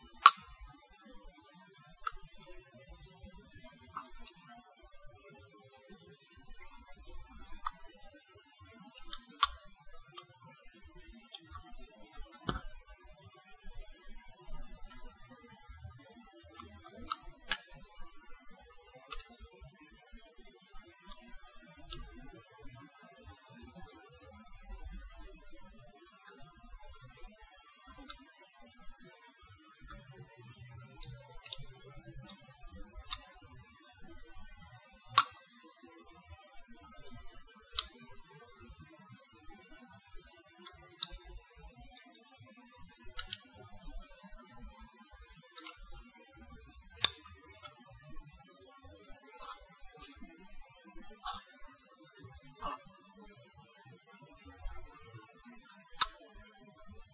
噪音 " 金戒指
标签： 碰杯所述的 无比 金属 戒指 铮铮
声道立体声